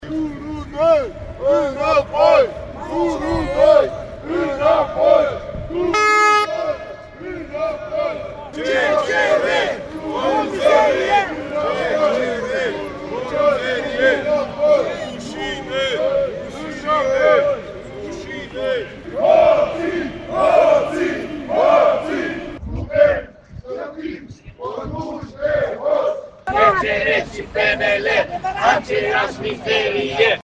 La Timișoara, zeci de persoane manifestează pașnic în centrul orașului. După aflarea deciziei CCR, manifestanții au început să mărșăluiască între Catedrală și Operă.
01-ambianta-TM-20.mp3